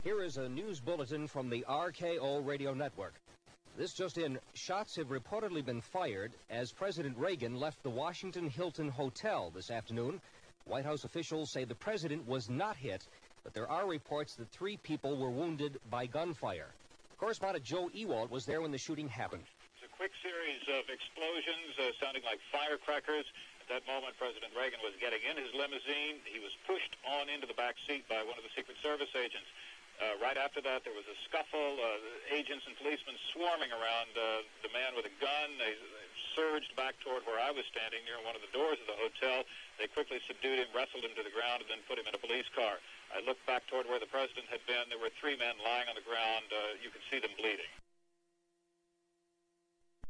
Hear news of Ronald Reagan getting shot by Hinkley.